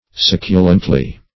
succulently - definition of succulently - synonyms, pronunciation, spelling from Free Dictionary Search Result for " succulently" : The Collaborative International Dictionary of English v.0.48: Succulently \Suc"cu*lent*ly\, adv.